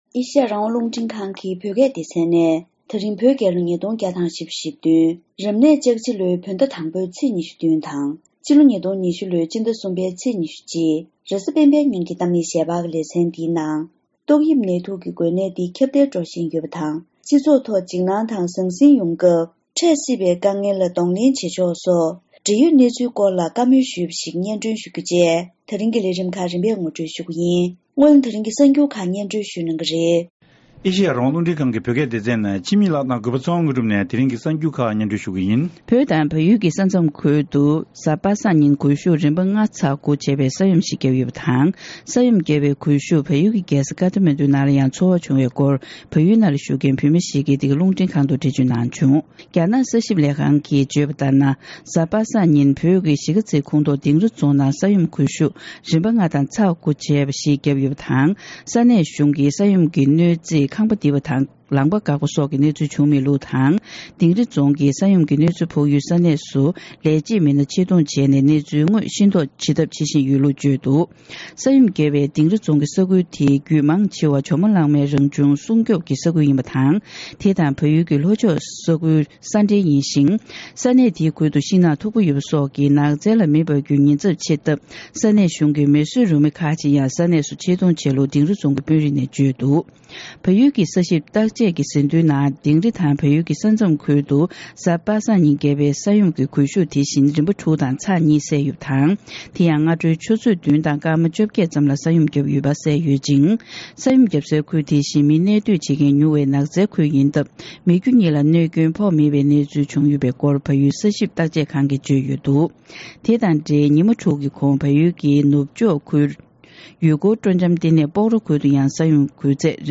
དེ་རིང་གི་གཏམ་གླེང་ཞལ་པར་ལེ་ཚན་ནང་འཛམ་གླིང་ཡུལ་གྲུ་གང་སར་ཏོག་དབྱིབས་ནད་དུག་གི་འགོས་ནད་འདི་ཁྱབ་གདལ་འགྲོ་བཞིན་ཡོད་པ་དང་སྤྱི་ཚོགས་ཐོག་འཇིགས་སྣང་དང་ཟང་ཟིང་ཡོང་དུས་འཕྲད་སྲིད་པའི་དཀའ་ངལ་ལ་གདོང་ལེན་བྱེད་ཕྱོགས་དང་བོད་པ་ཚོས་གཙང་སྦྲ་སོགས་ཁྱོན་ཡོངས་ནས་དོ་སྣང་ཇི་ལྟར་དགོས་ཚུལ་ཐད་སྤྱི་ཚོགས་འཕྲོད་བསྟེན་གྱི་ཆེད་ལས་མི་སྣ་དང་ལྷན་དུ་བཀའ་མོལ་ཞུས་པ་ཞིག་གསན་རོགས་གནང་།